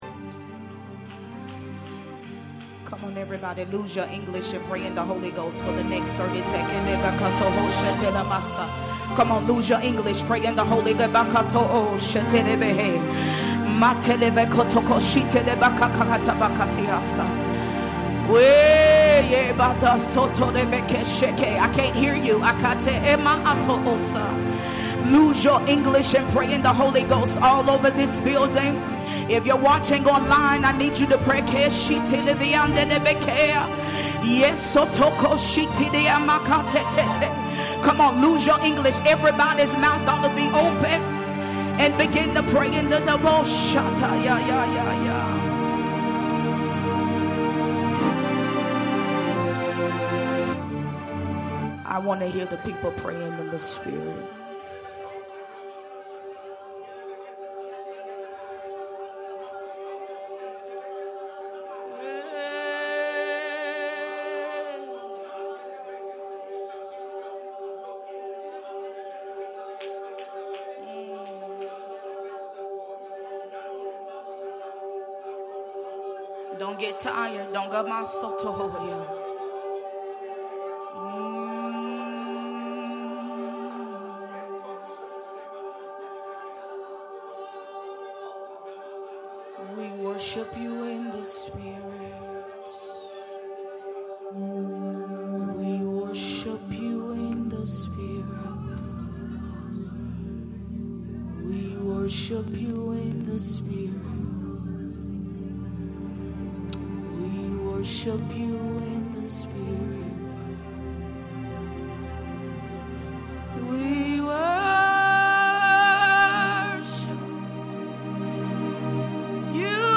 Spontaneous Worship & Praise